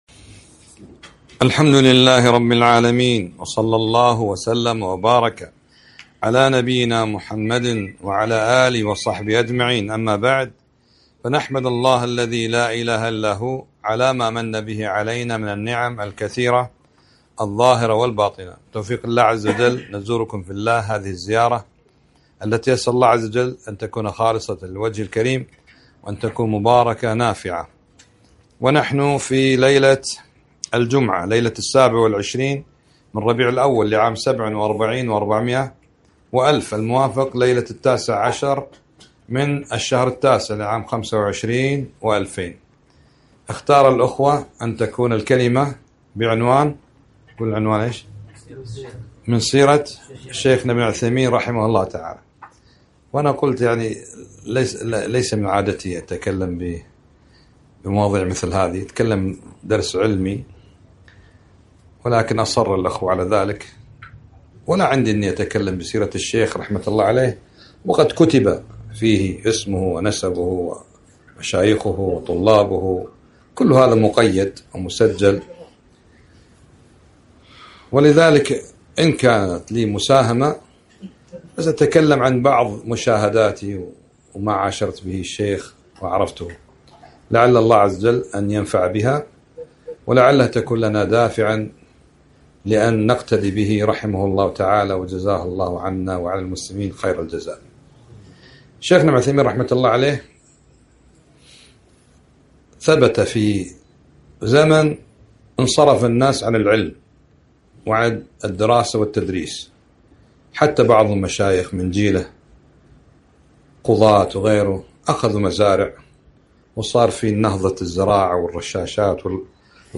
محاضرة - من سيرة الشيخ ابن عثيمين رحمه الله